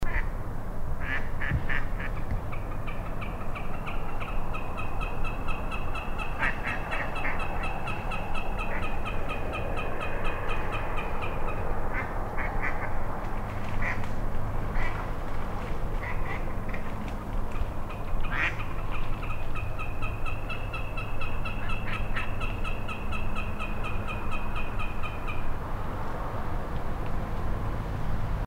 Onneksi kohta alkoi rannasta kuulua outoa taivaanvuohimaista kitkatusta, joka aika ajoin kohosi lähes falsettimaiseksi kikityksesti –
Äänittäminen oli mahdotonta ennen kuin lähes kaikki bongarit olivat lähteneet ja lopulta sain jonkinlaiset äänitykset linnusta maassa ja vielä puolisen tuntia sormet kohmeessa odoteltuani lintu nousi taas taivaalle soimaan ja sain myös puputuksen äänitettyä!